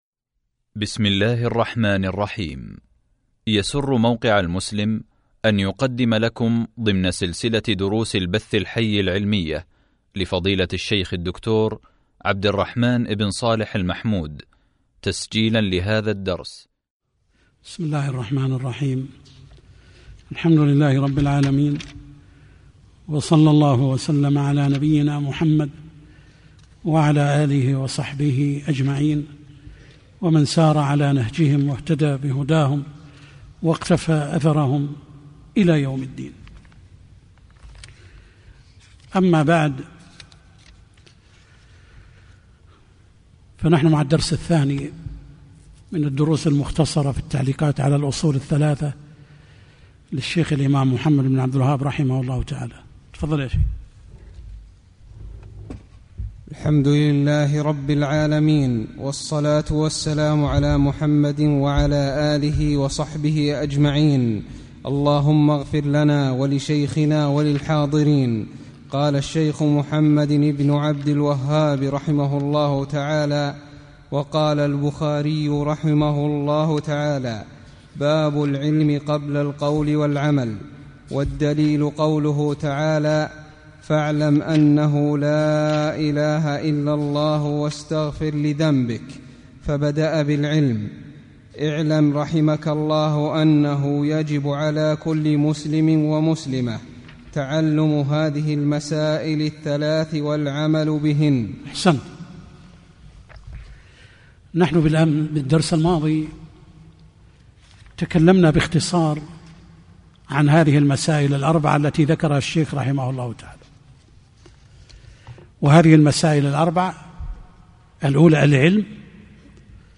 شرح الأصول الثلاثة | الدرس 2 | موقع المسلم